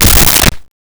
Creature Footstep 03
Creature Footstep 03.wav